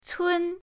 cun1.mp3